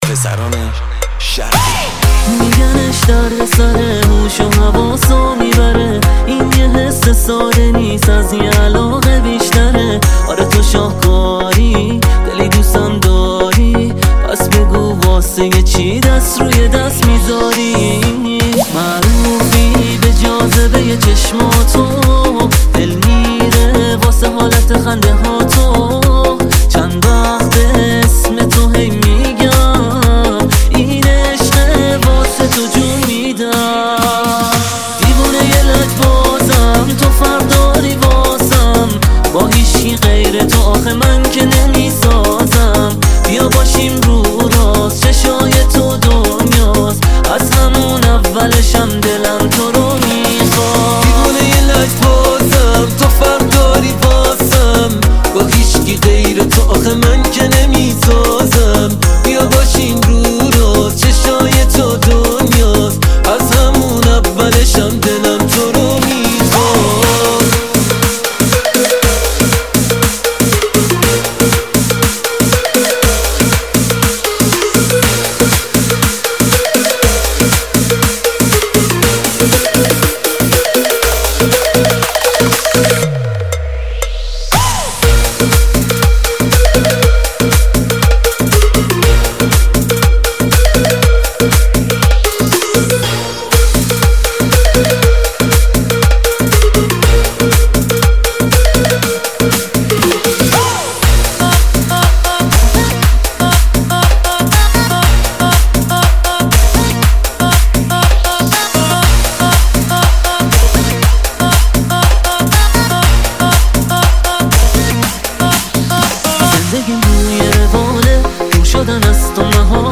موسیقی